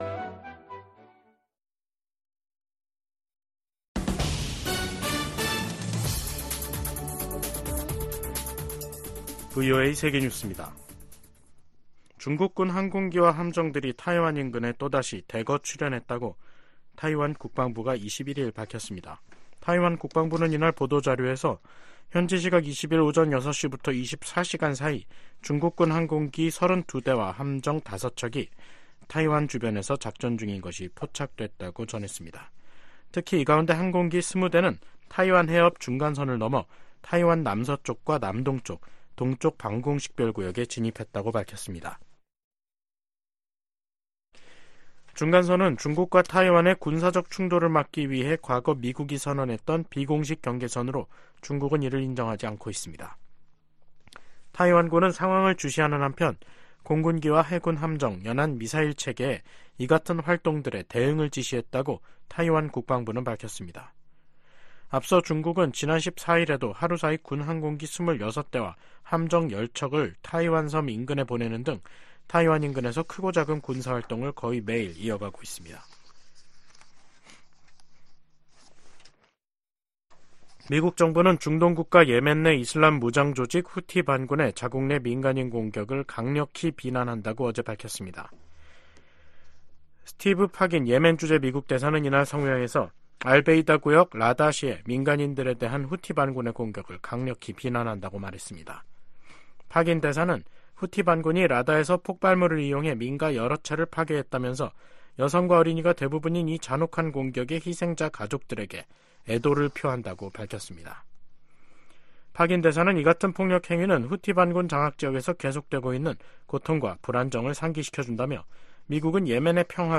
VOA 한국어 간판 뉴스 프로그램 '뉴스 투데이', 2024년 3월 21일 3부 방송입니다. 북한이 영변 핵시설에서 핵탄두 소형화에 필수적인 삼중수소 생산 시설을 가동 중이라는 위성사진 분석 결과가 나왔습니다. 북한이 러시아와 관계를 강화하면서 국제 질서를 위협하고 있다고 주한미군사령관이 지적했습니다. 블라디미르 푸틴 러시아 대통령의 5연임이 확정되면서 북한과 러시아 밀착에 탄력이 붙을 것이란 전망이 나옵니다.